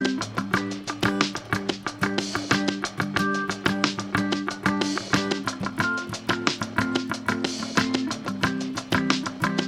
50 of the most popular modern worship songs
• Sachgebiet: Praise & Worship